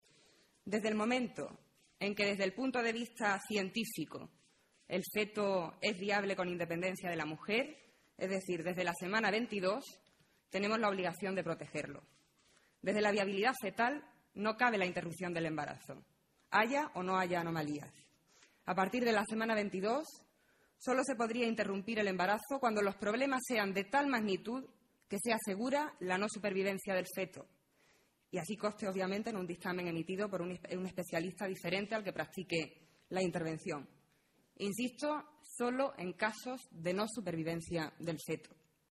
En comparecencia ante los medios de comunicación, Aído ha manifestado además que a partir de la semana 22 de gestación, coincidiendo con el inicio de la viabilidad fetal, primará la protección del feto.